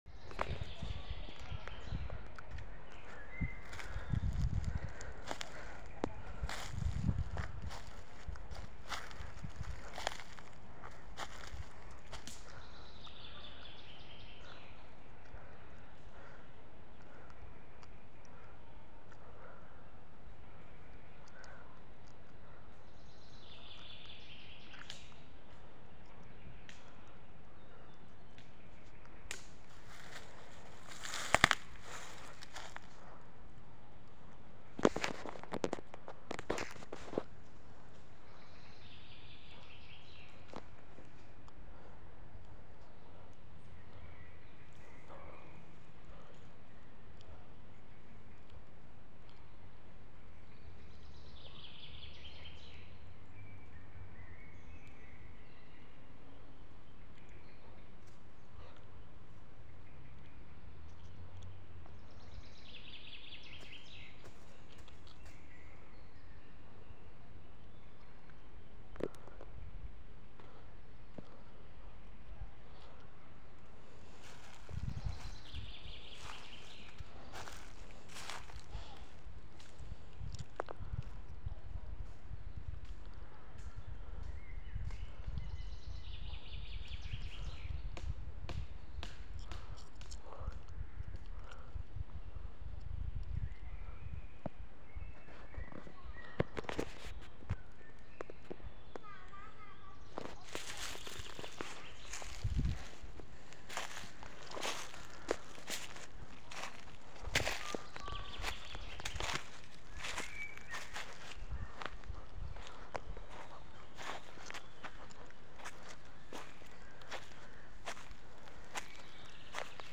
Nagranie odgłosów natury
Zalacznik-3-–-nagranie-odglosow-natury-1.mp3